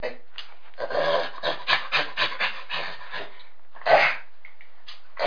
دانلود صدای شامپانزه برای کودکان از ساعد نیوز با لینک مستقیم و کیفیت بالا
جلوه های صوتی